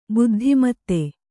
♪ buddhi matti